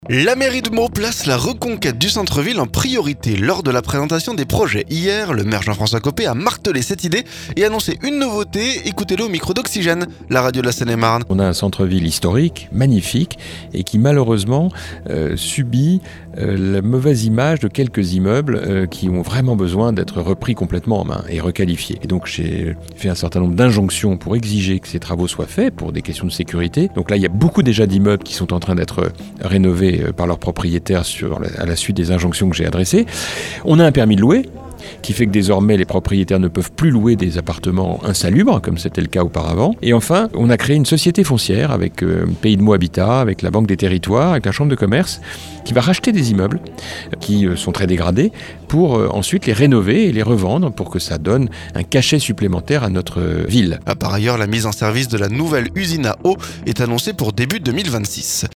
La mairie de Meaux place la reconquête du centre-ville en priorité... Lors de la présentation des projets hier, le maire Jean-François Copé, a martelé cette idée...
Ecoutez le au micro d'Oxygène, la radio de la Seine-et-Marne.